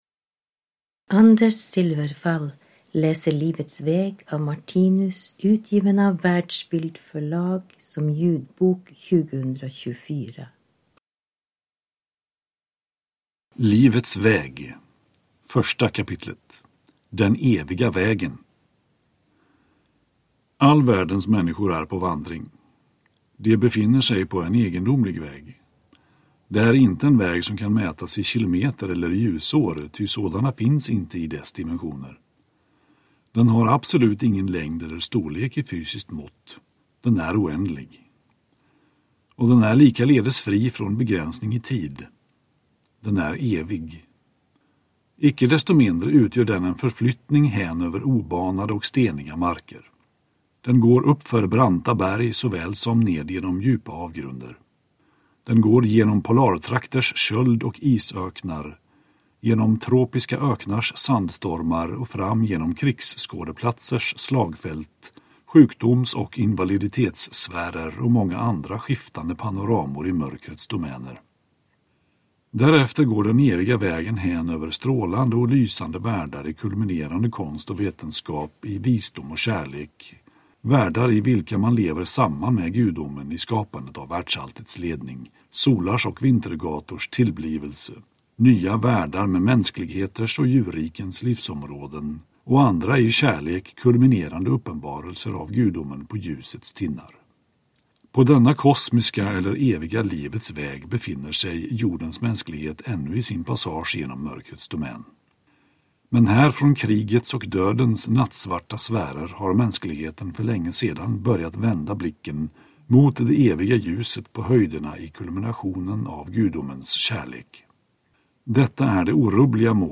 Livets väg – Ljudbok